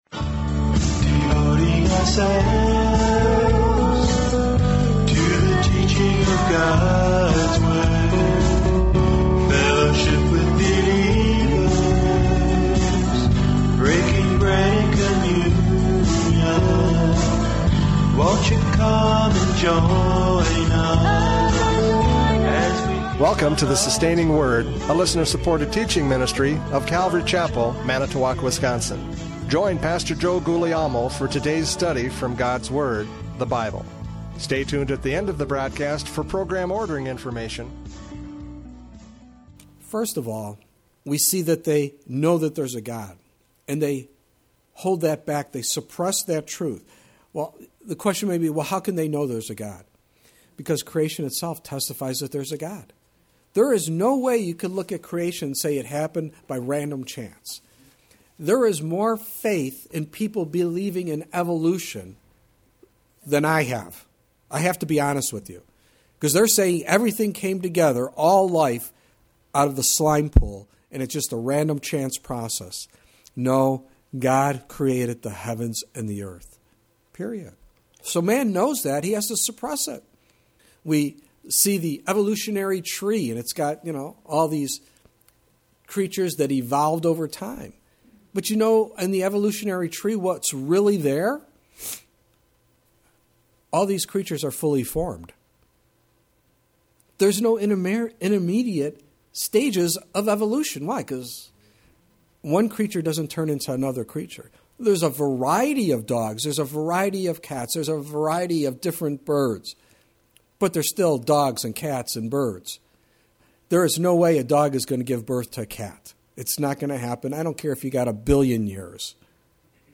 Judges 5:6-8 Service Type: Radio Programs « Judges 5:6-8 Life Under Oppression!